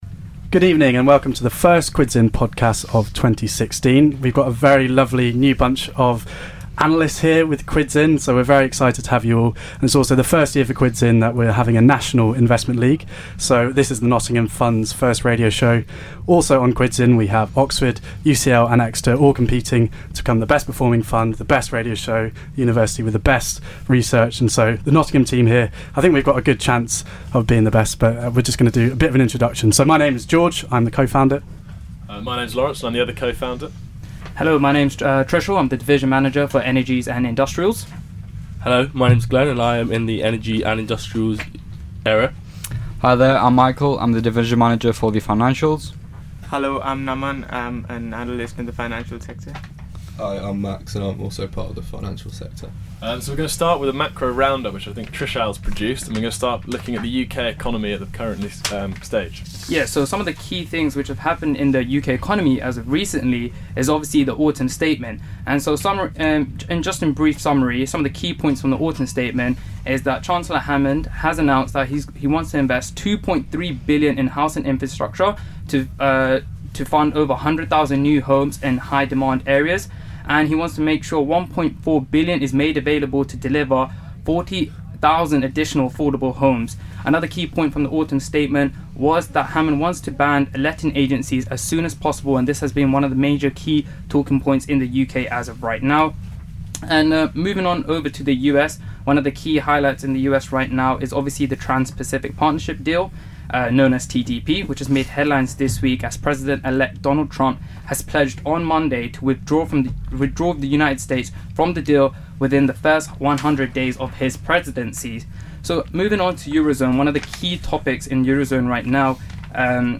The first radio show from the Quids In Nottingham fund.